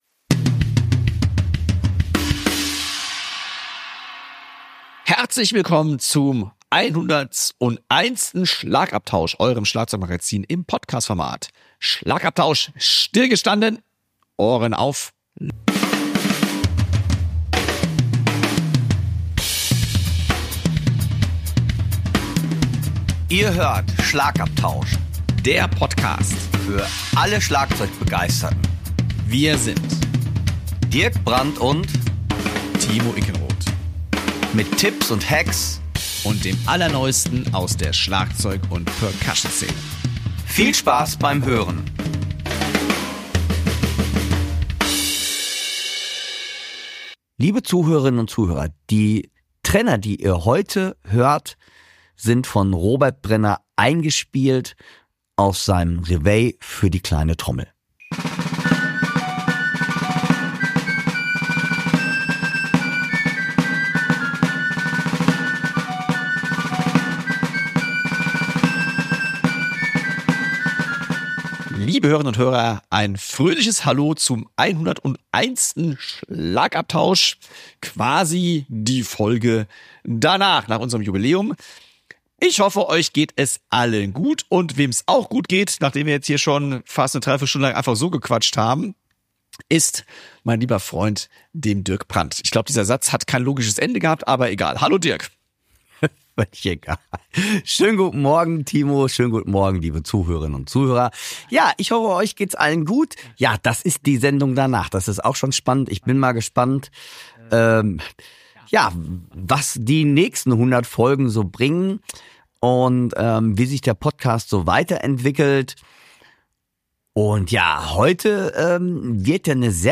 intensivem Fachgespräch